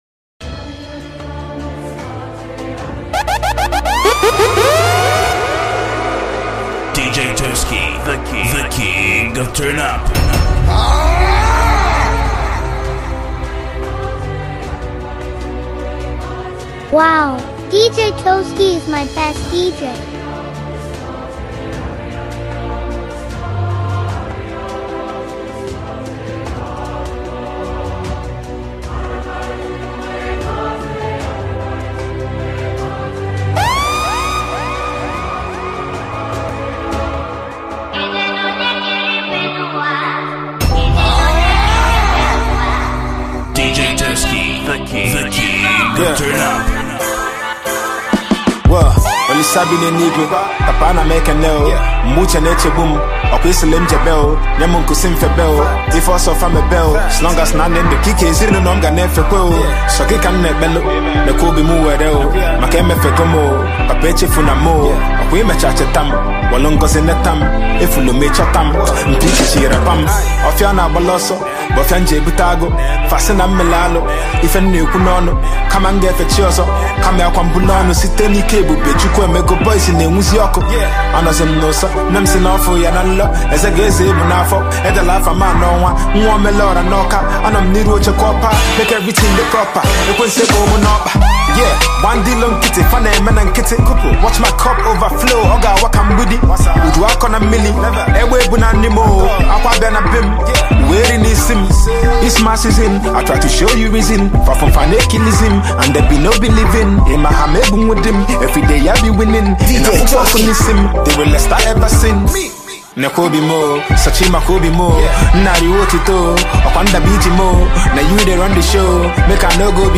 This dj mix consist of trending hit songs in 2025.